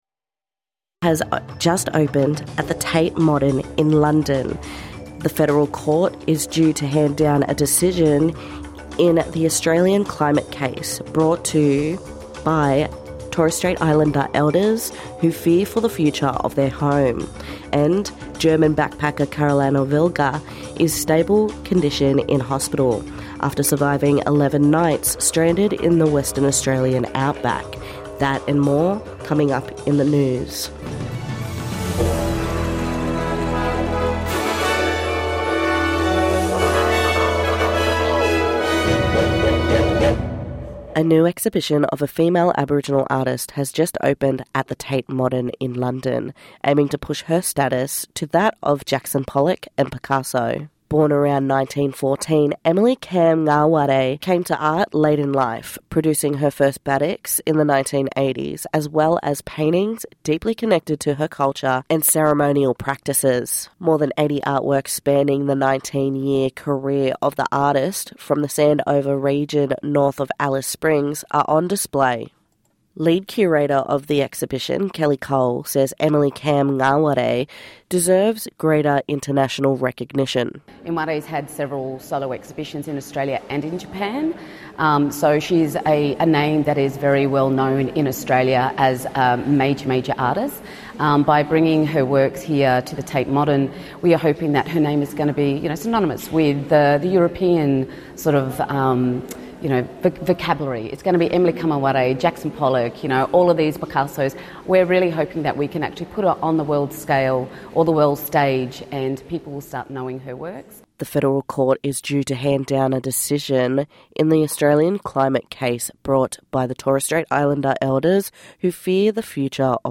NITV Radio NEWS 14/07/2025